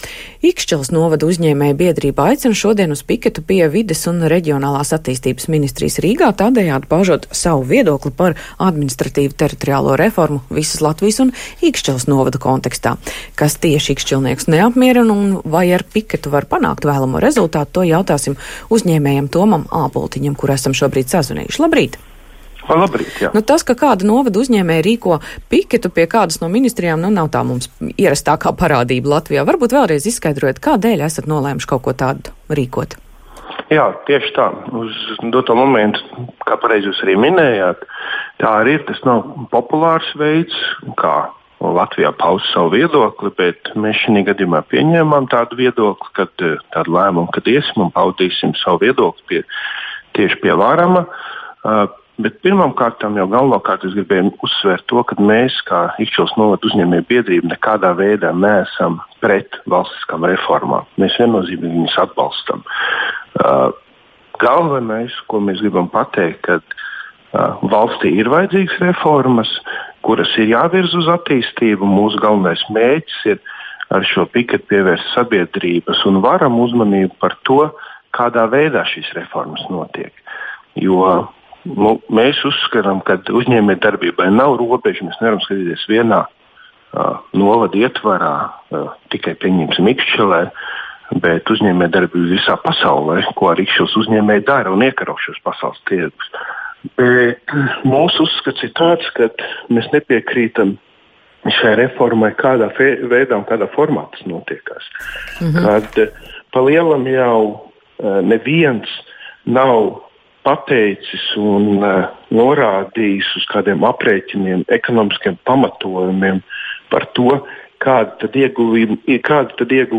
telefonintervijā Latvijas Radio